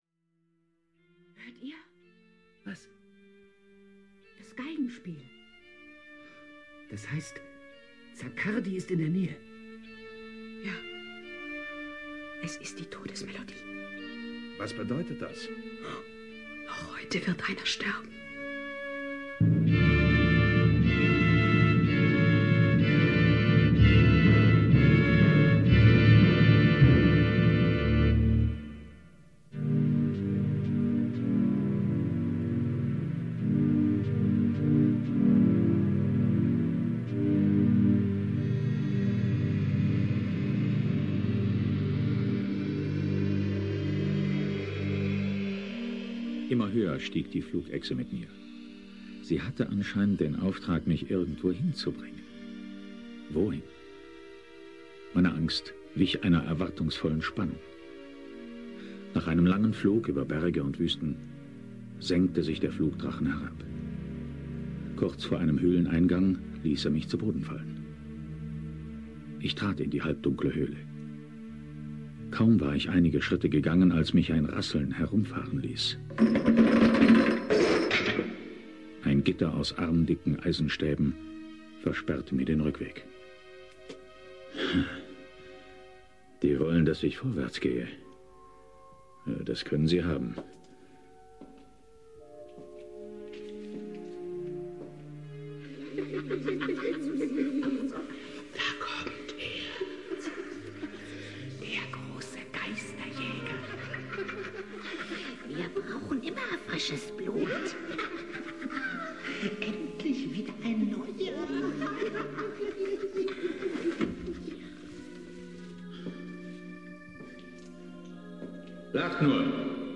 Atmosphärisch zeigt sich die Produktion in Bestform. Die Klanggestaltung ist bewusst reduziert, entfaltet jedoch eine starke Wirkung. Dumpfe Geräusche, beklemmende Stille und eine sparsam eingesetzte musikalische Untermalung erzeugen eine dichte, unheilvolle Stimmung.
Sie sind markant, direkt und mitunter leicht theatralisch, dabei jedoch stets engagiert. Besonders die Darstellung des Protagonisten überzeugt durch eine glaubhafte Mischung aus Verzweiflung und Entschlossenheit. Die Inszenierung verzichtet auf moderne Effekte und bleibt dem klassischen Hörspielstil treu, wodurch der nostalgische Charakter der Folge zusätzlich unterstrichen wird.